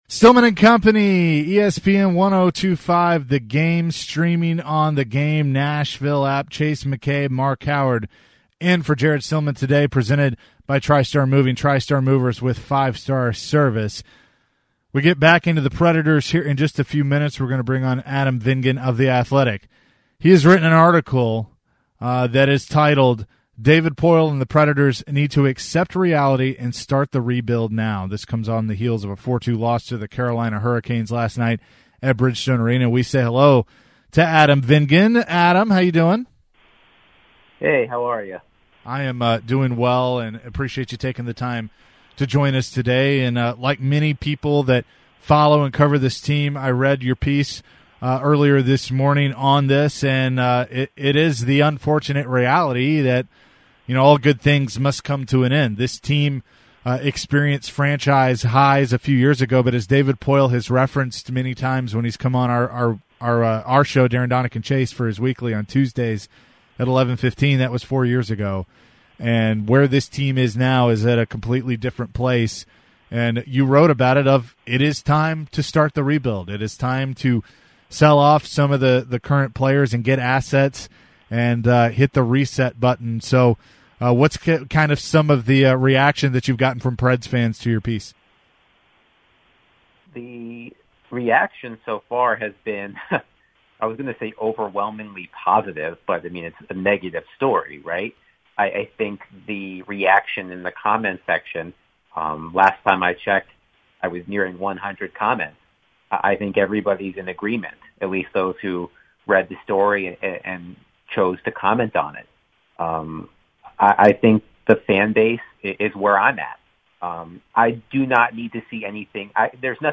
We take your calls and texts on the Preds.